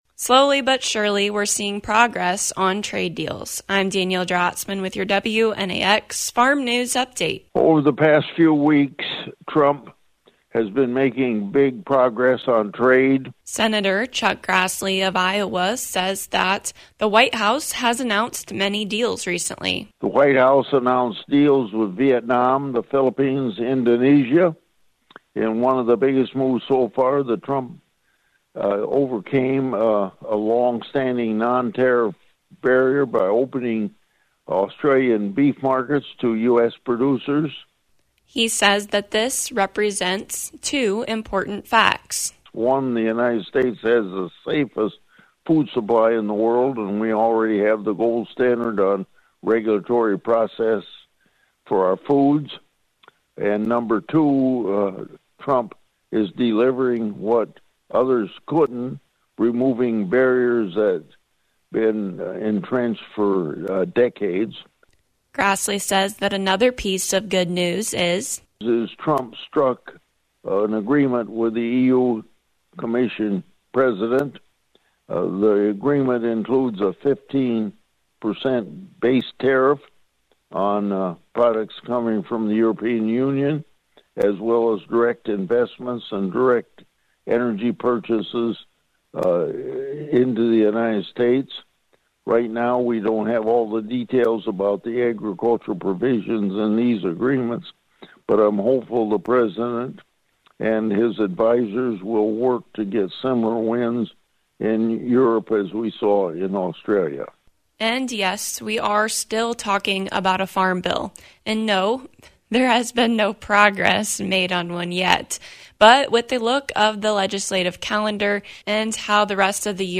Slowly but surely, we are seeing progress on trade deals and a farm bill. Hear from Senator Chuck Grassley.